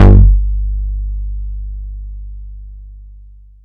Bass Pluck - R.I.P. SCREW [ G ].wav